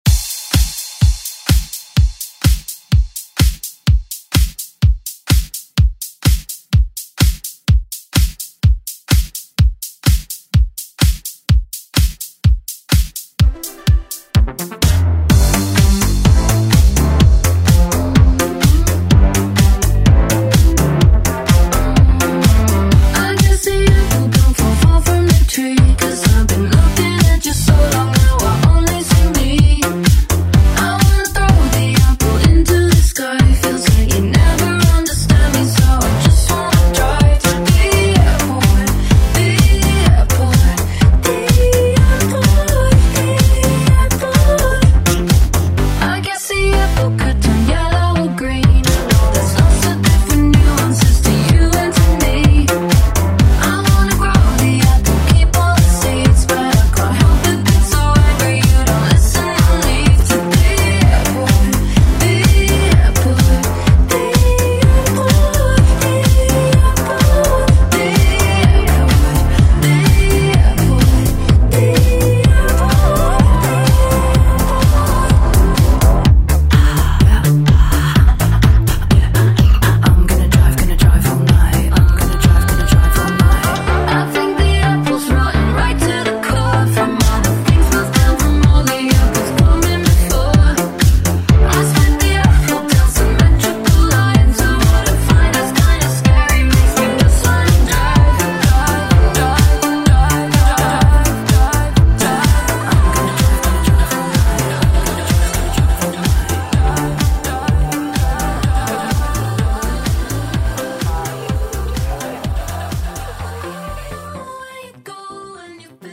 Electronic Pop Dance Music Extended ReDrum Clean126 bpm
Genre: Version: BPM: 126 Time: 3:05